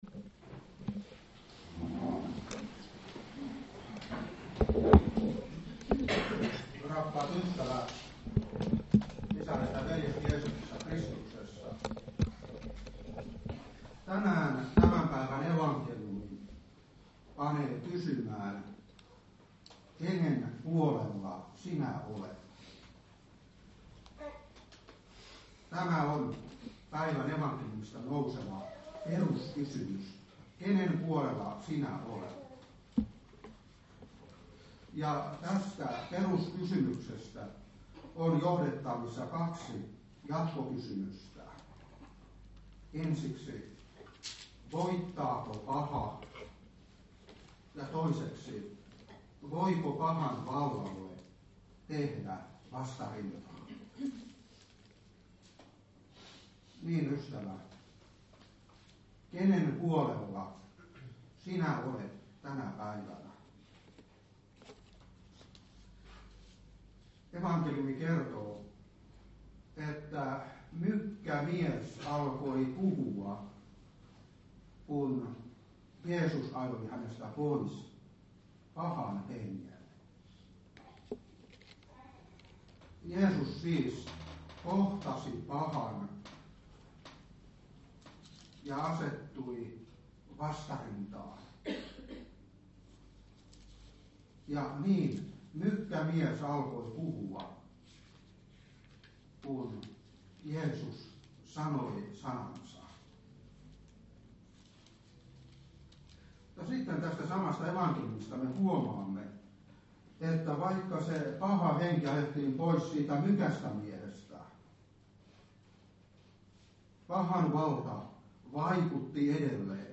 Saarna 2016-2.